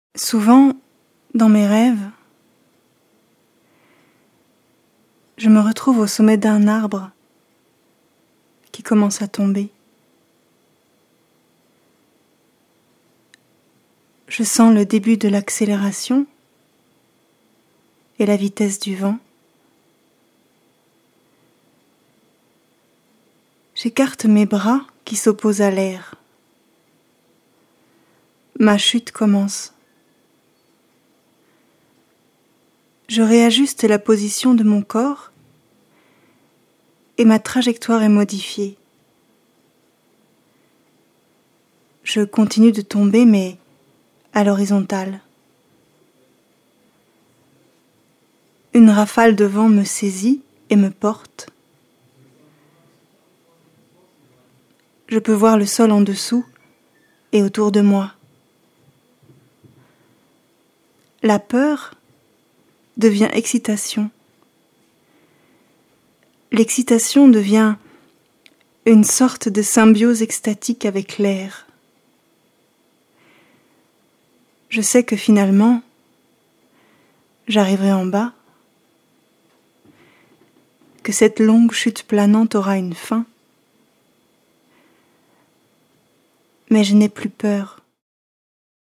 » Französich f.